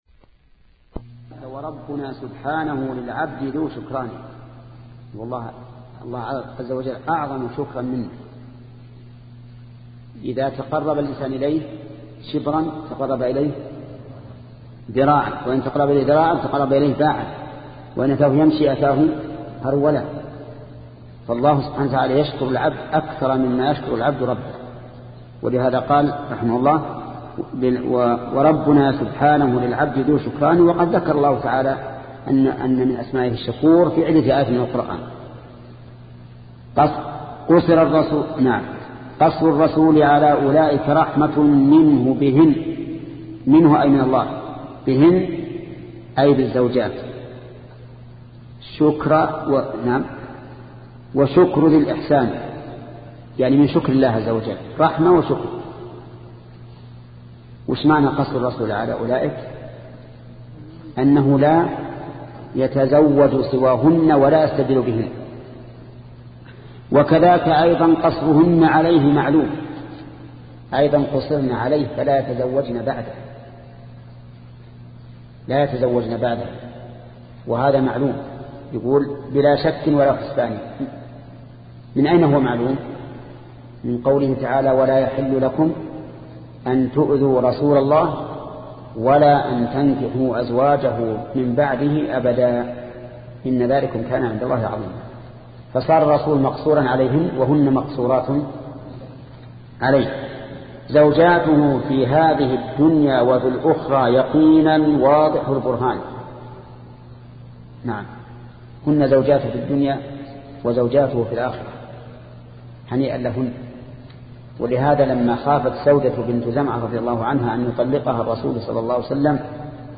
شبكة المعرفة الإسلامية | الدروس | التعليق على القصيدة النونية 31 |محمد بن صالح العثيمين